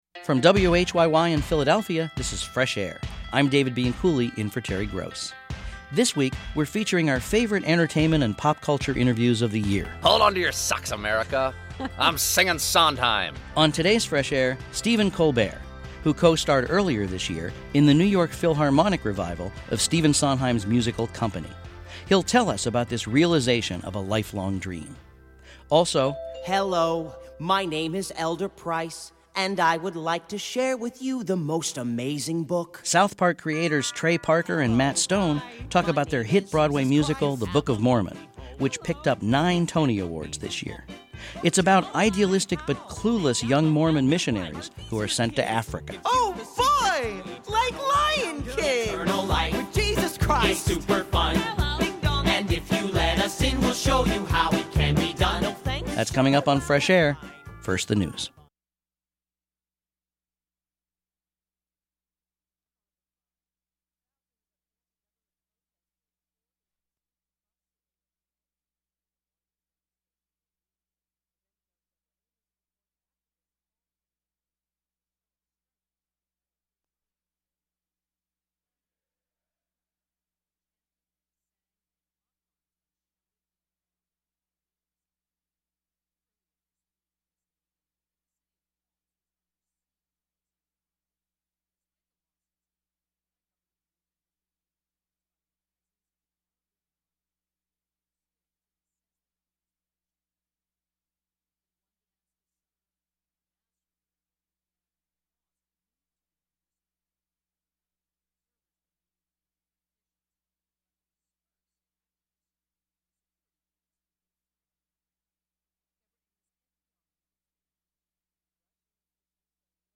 This interview was originally broadcast on May 19, 2011.